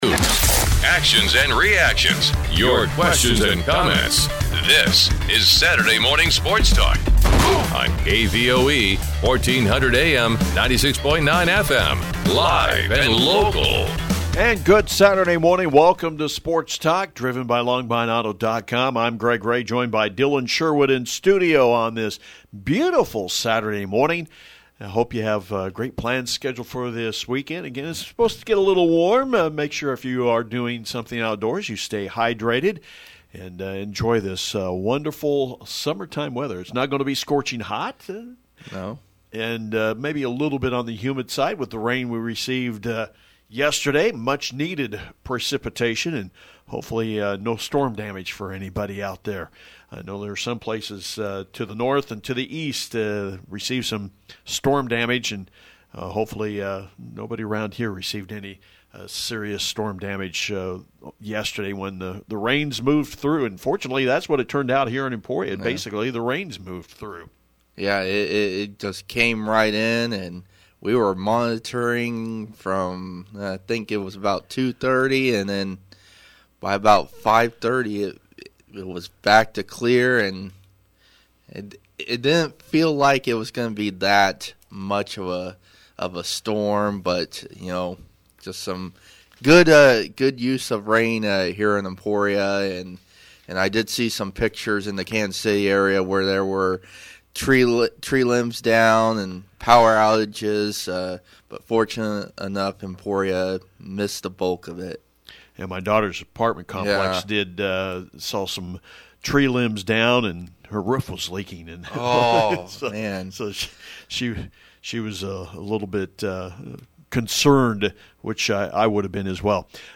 Sports Talk